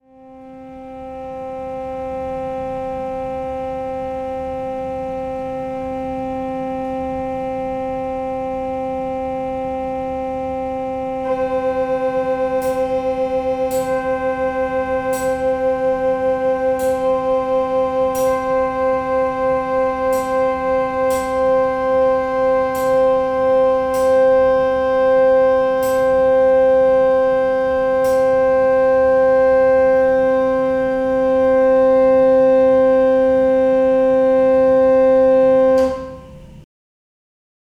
Pipe Beats
As an example I have both a picture and a sound clip of two pipes 'beating' as they come closer in pitch.
In the sound clip you first hear middle c. Then after a few seconds the c one octave above is played. It's a little flat and you can hear the sound 'wavering' or 'beating'. You then hear me tapping on the tuning collar raising the pitch. As each tap occurs the beats slow down. At the end of the clip my digital tuner claimed the pipes were in tune but a listen shows they were still slowly beating so the tuning is not perfect.
pipe_beats.mp3